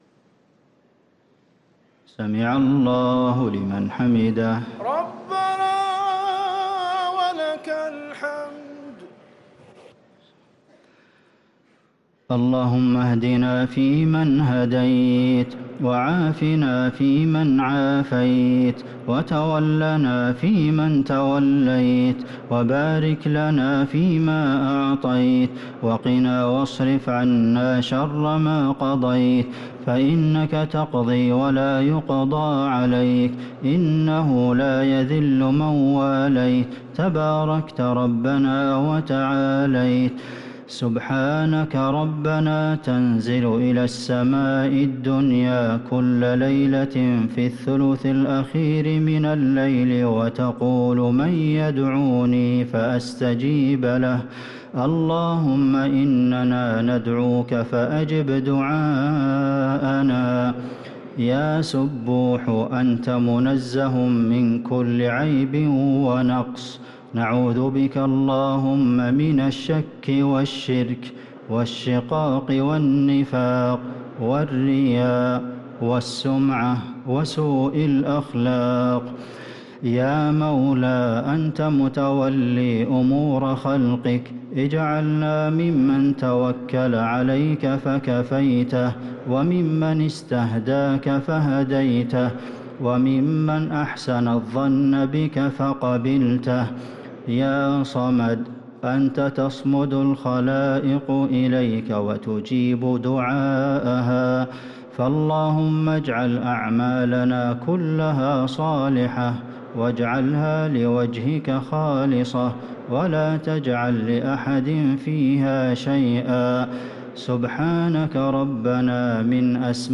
دعاء القنوت ليلة 23 رمضان 1444هـ | Dua for the night of 23 Ramadan 1444H > تراويح الحرم النبوي عام 1444 🕌 > التراويح - تلاوات الحرمين